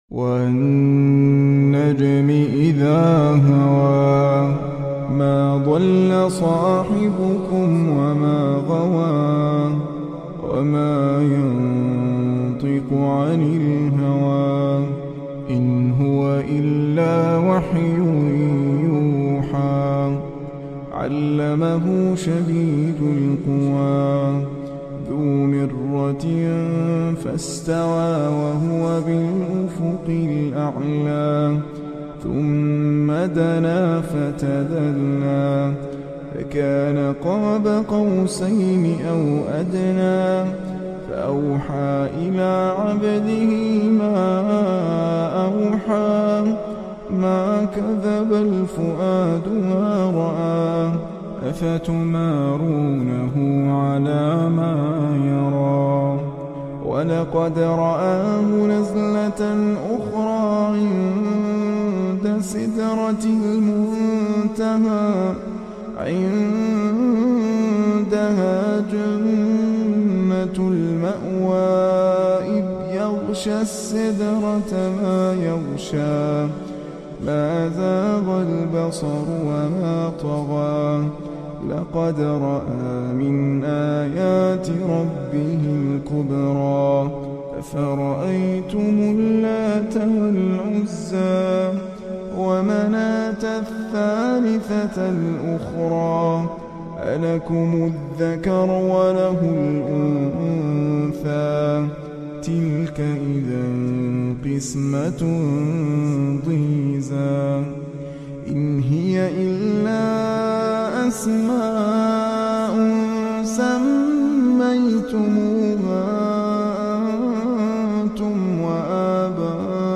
Heart Melting Voice❤Soothing Quran Recitation